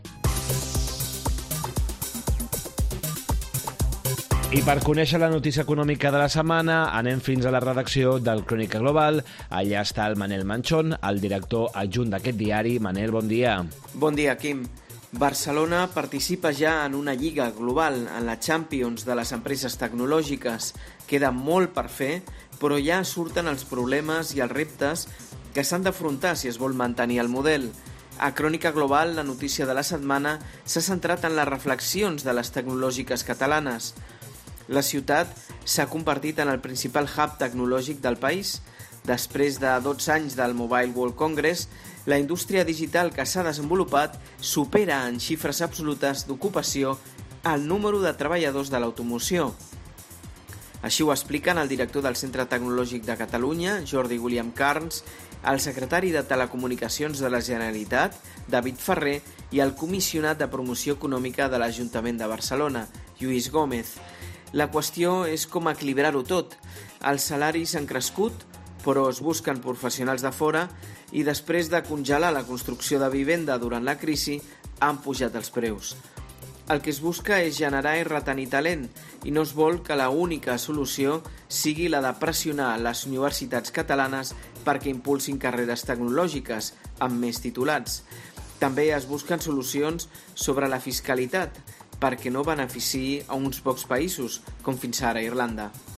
ens fa el seu comentari setmanal